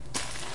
描述：与植物相撞